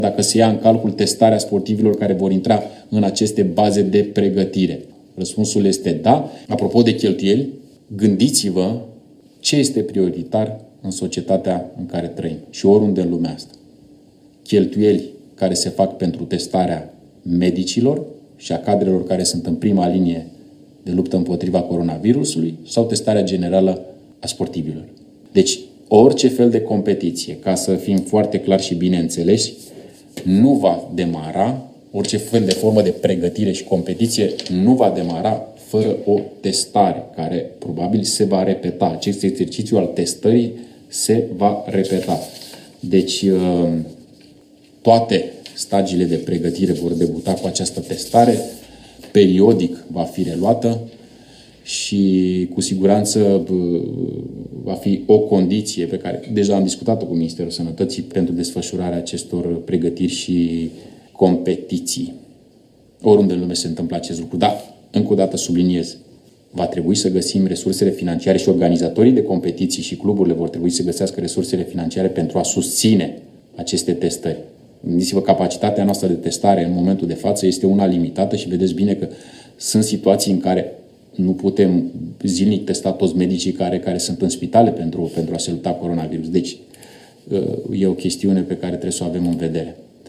Într-0 conferință online, ministrul Tineretului şi Sportului a anunțat că, după relaxarea restricţiilor impuse pe perioada stării de urgență, toţi sportivii vor fi testaţi pentru SARS-CoV-2 înaintea intrării în cantonament.